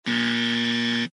wrongbuzzer quiet
wrongbuzzer-quiet.mp3